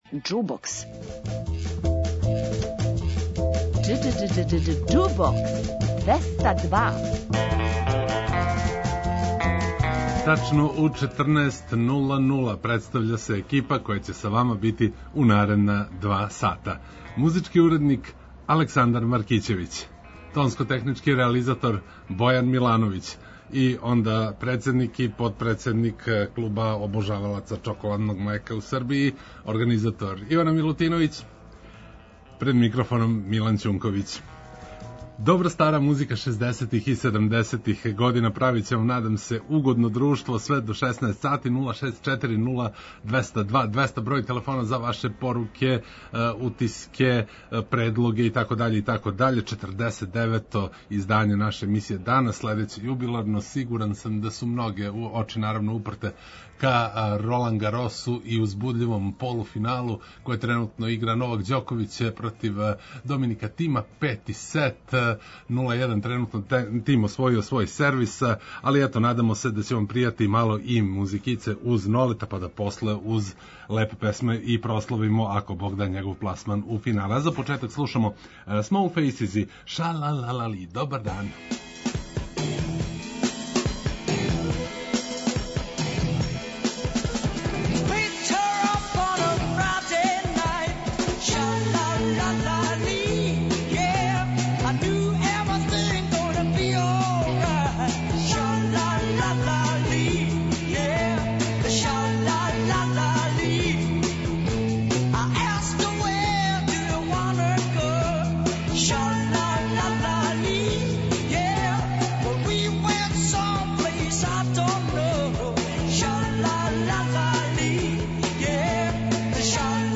Поново ћемо ове суботе да слушамо само светске хитове шездесетих и седамдесетих, нећемо да силазимо на домаћи терен.